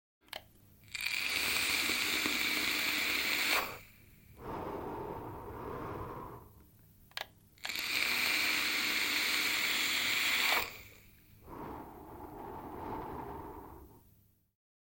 На этой странице собраны звуки электронных сигарет: шипение, бульканье, парение и другие эффекты.
Звук испарения электронной сигареты при курении